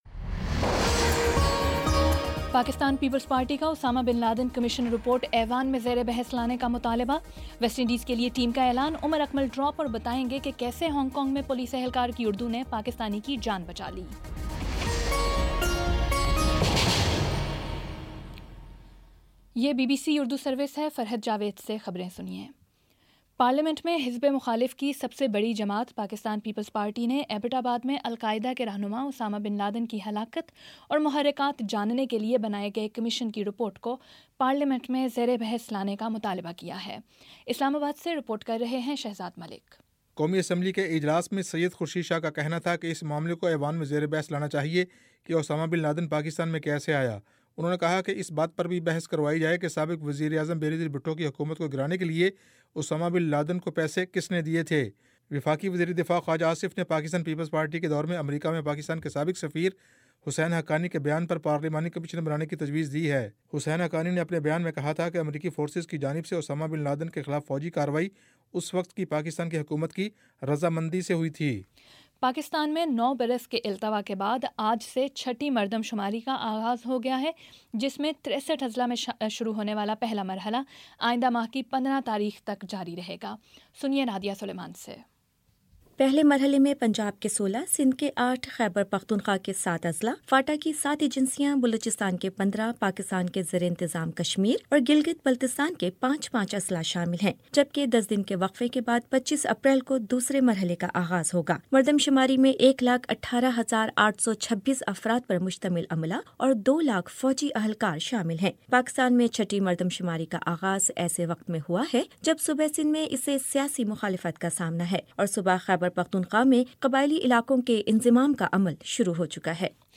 مارچ 15 : شام پانچ بجے کا نیوز بُلیٹن